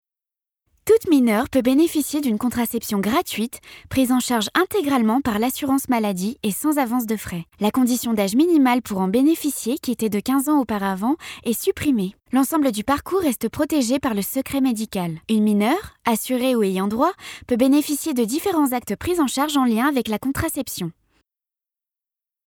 Voix off
Institutionel
5 - 32 ans - Soprano